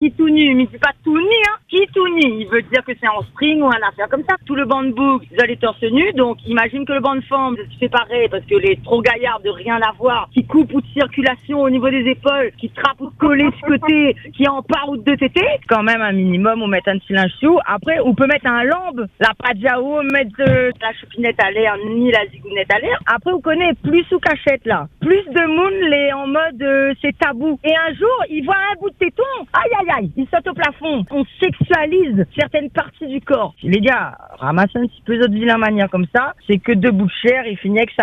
Une nouvelle intervention diffusée sur l’antenne illustre cette diversité d’opinions et confirme que le débat est loin d’être tranché.